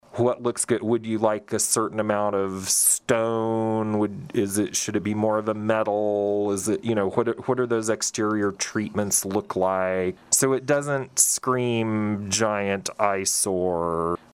Saline County Administrator Phillip Smith-Hanes joined in on the KSAL Morning News Extra with a look at the proposed facility that would have 392 beds in pods that could flex as male and female inmate populations change.